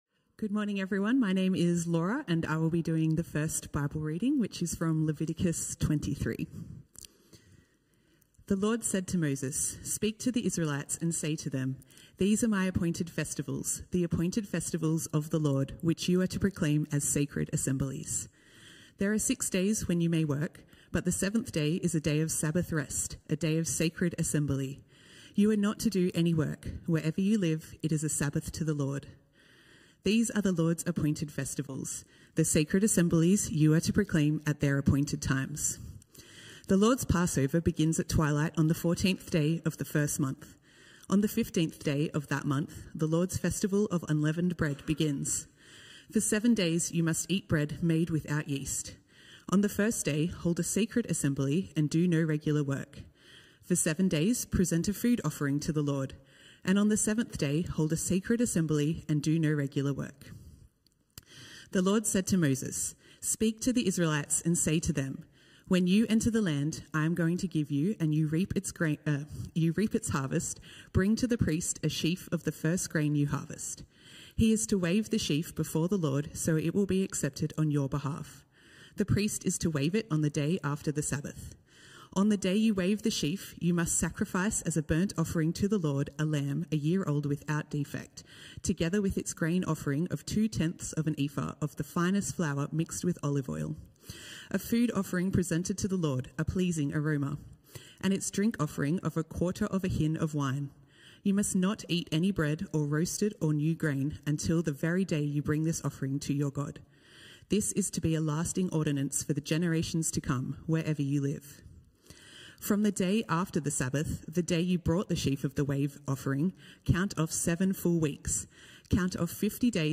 A message from the series "Called."